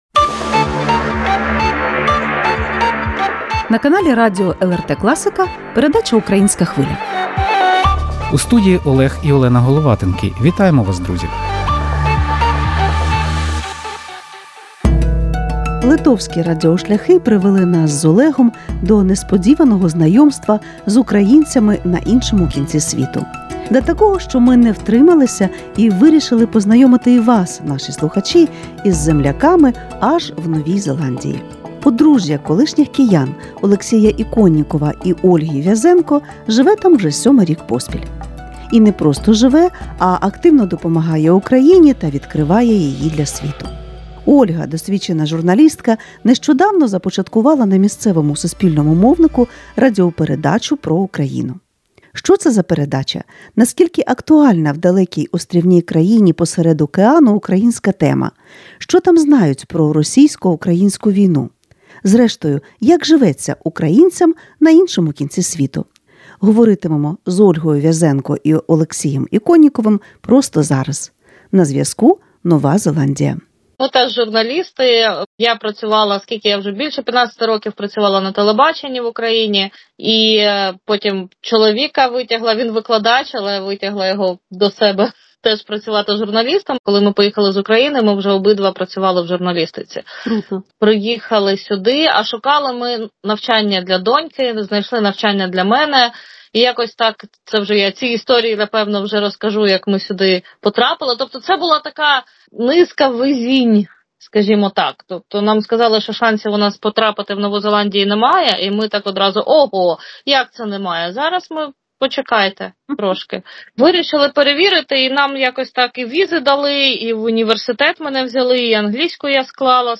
У дружній розмові українських медійників дізнаємося про таке: